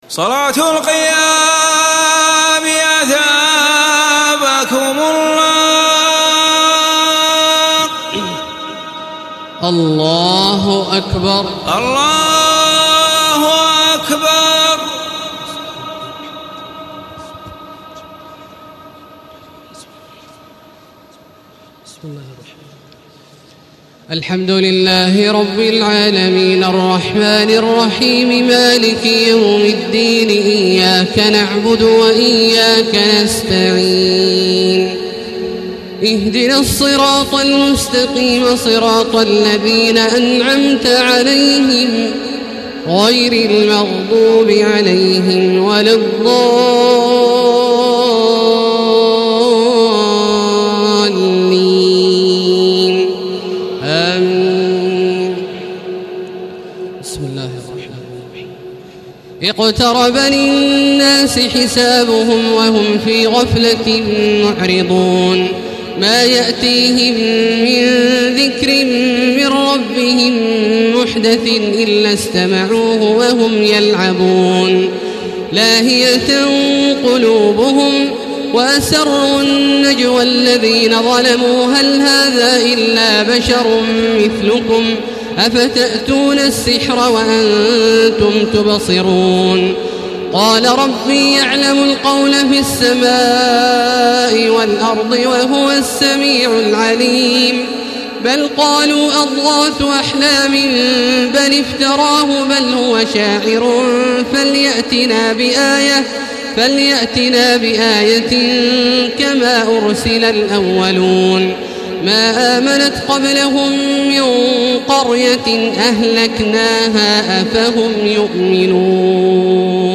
تراويح الليلة السابعة عشر رمضان 1435هـ سورة الأنبياء كاملة Taraweeh 17 st night Ramadan 1435H from Surah Al-Anbiyaa > تراويح الحرم المكي عام 1435 🕋 > التراويح - تلاوات الحرمين